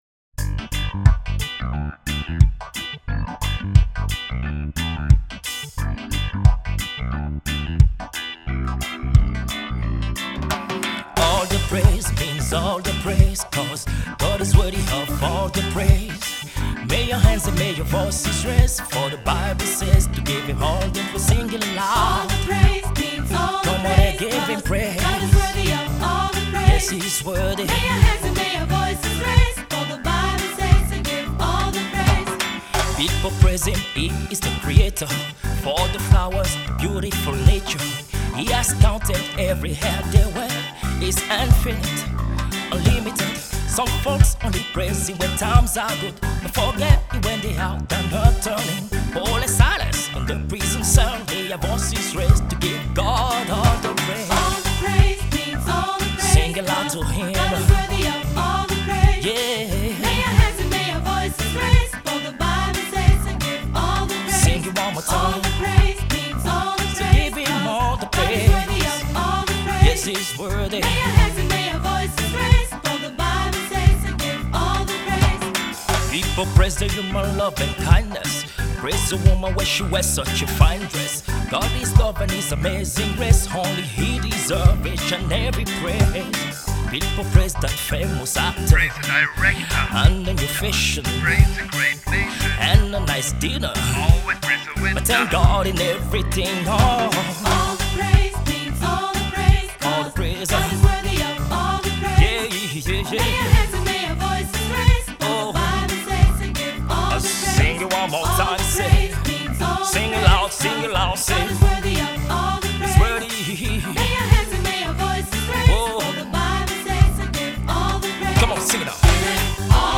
Reggae-Gospel-Songs
• SAB, auch SSA, Solist + Piano